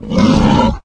boar_pain_1.ogg